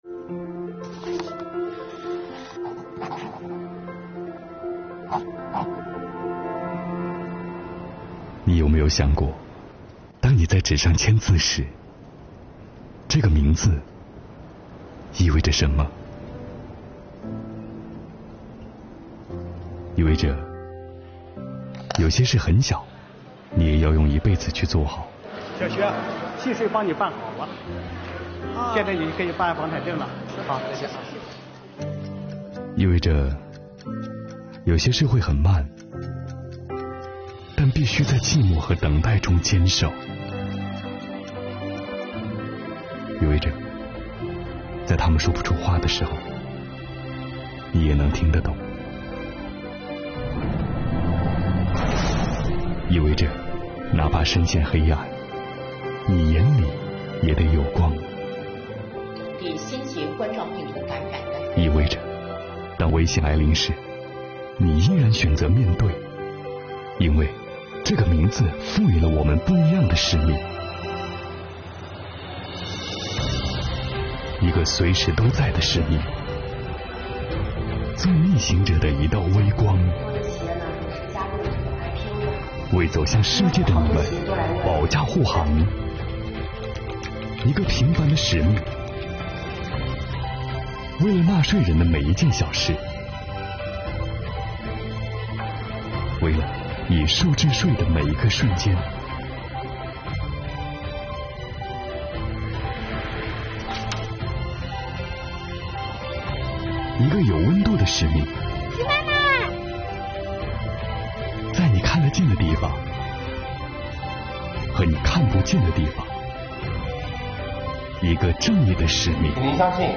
作品前半程节奏舒缓，将税务人的工作点滴娓娓道来，后半程逐渐明快，简洁凝练的旁白配以慷慨激昂的背景音，充分体现出税务人对党的忠诚、对税收事业的热爱，戛然而止的结尾，更是恰到好处，振奋人心。